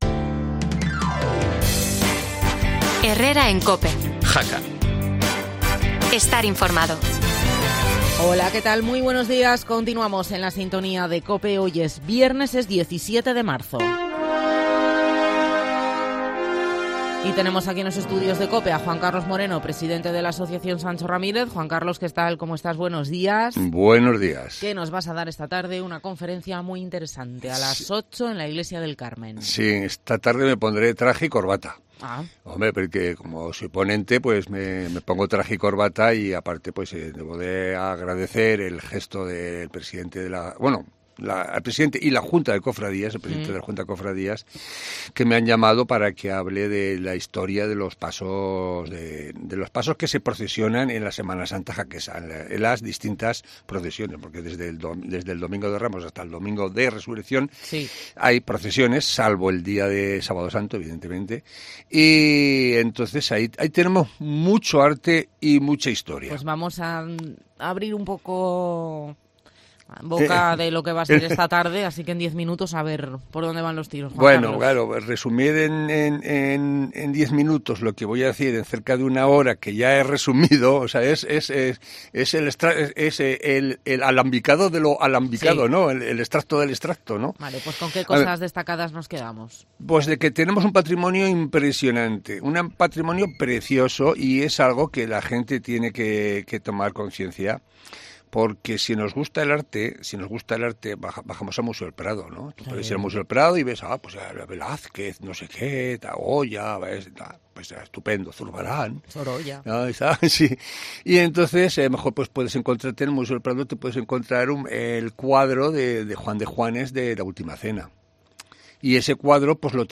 No te pierdas la entrevista en COPE y no faltes a la conferencia de esta tarde!!